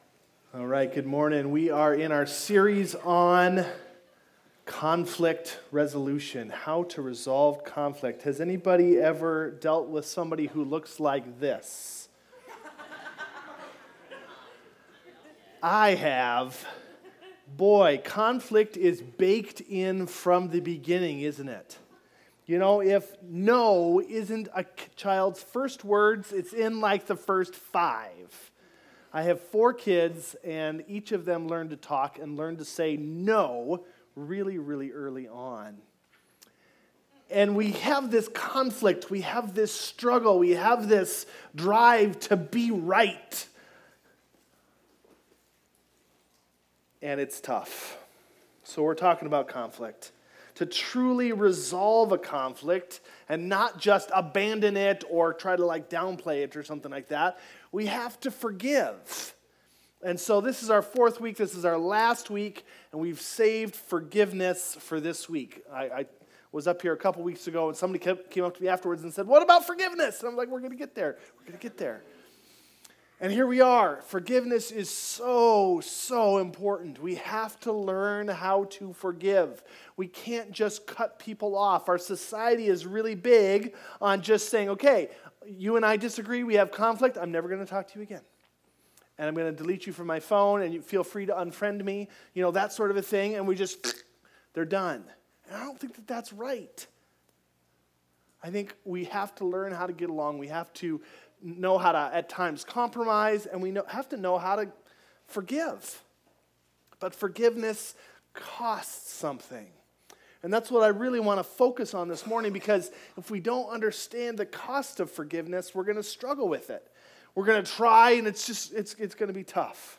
Video Audio Download Audio Home Resources Sermons The Cost of Forgiveness Nov 02 The Cost of Forgiveness Forgiveness is hard!